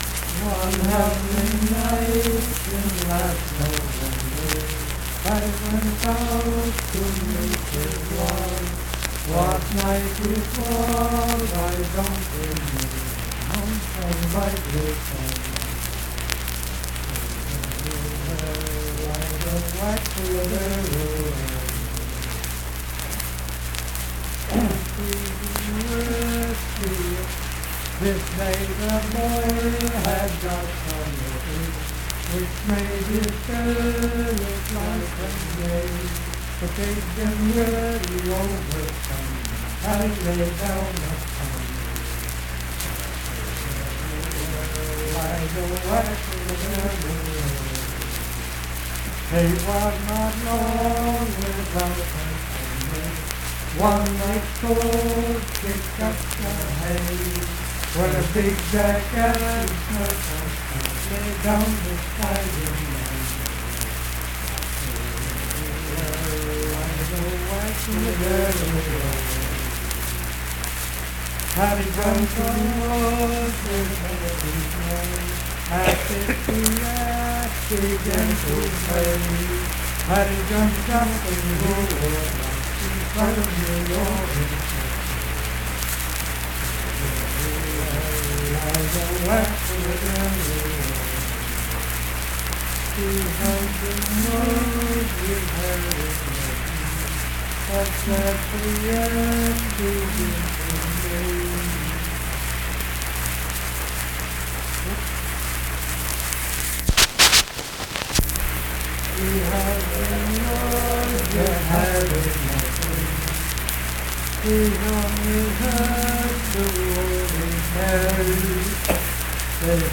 Unaccompanied vocal music
Richwood, Nicholas County, WV.
Verse-refrain 7(6).
Voice (sung)